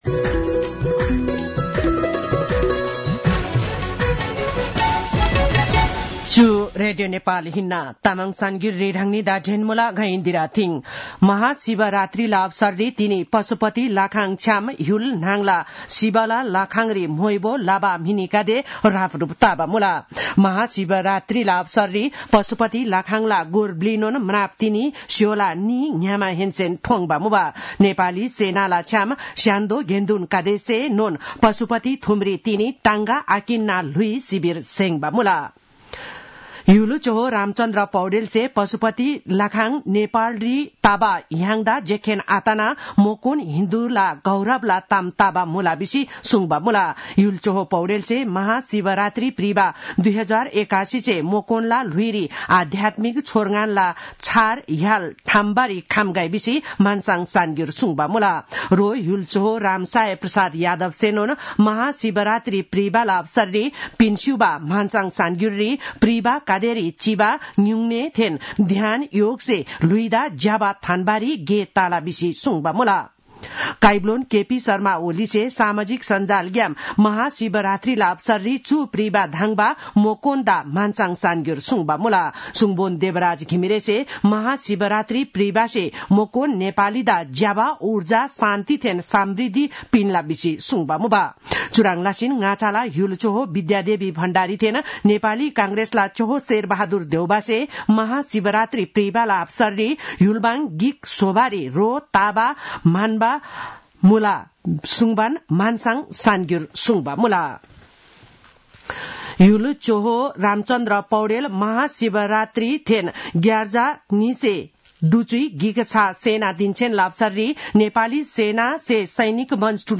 तामाङ भाषाको समाचार : १५ फागुन , २०८१